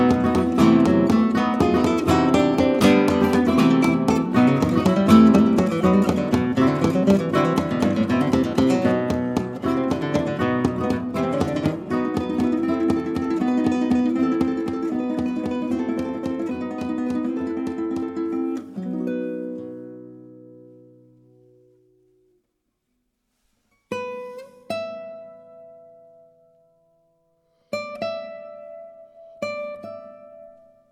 Guitare
Musiques du monde - Europe de l'Est et méridionale
studios La Buissonne